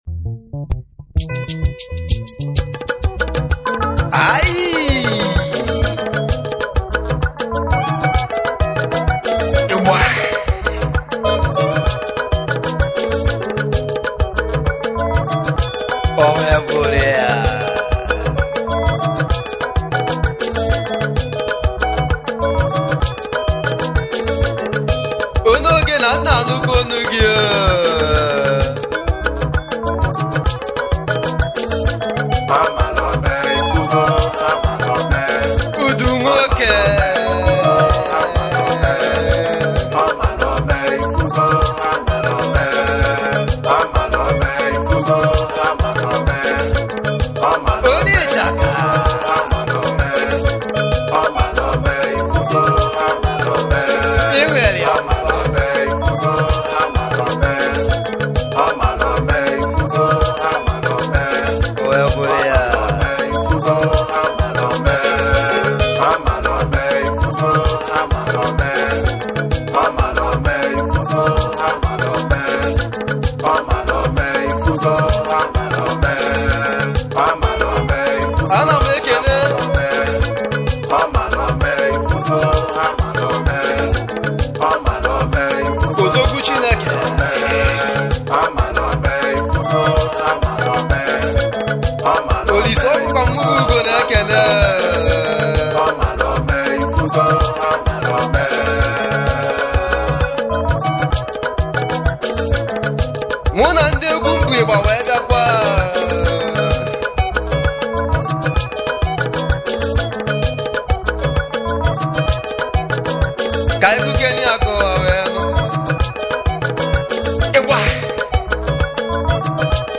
igbo highlife